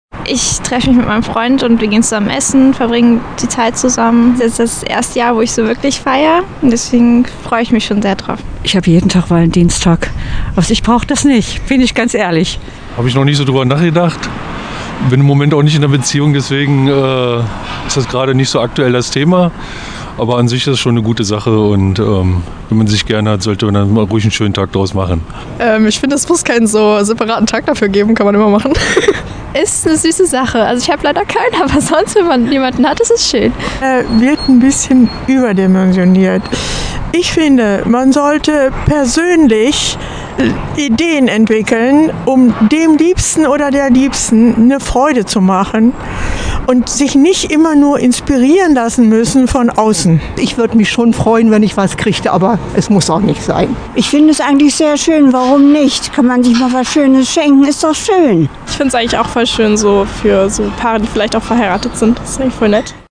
Umfrage-Valentinstag.mp3